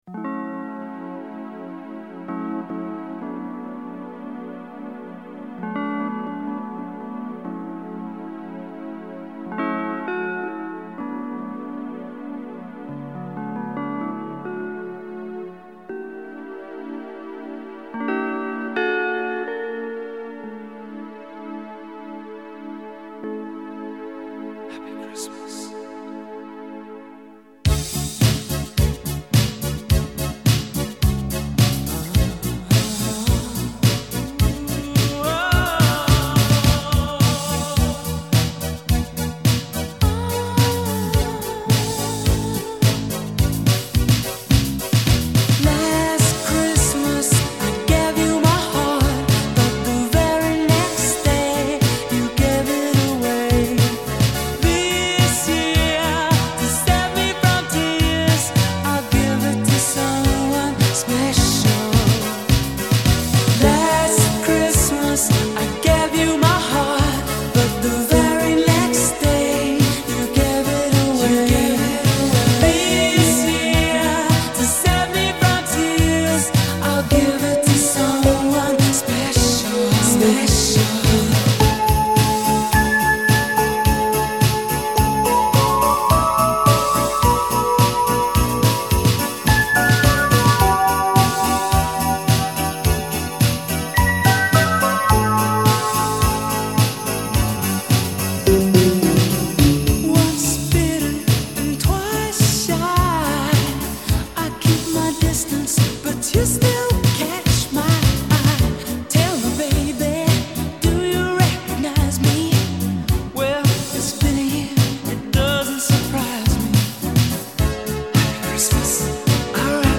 Песня с новогодним вайбом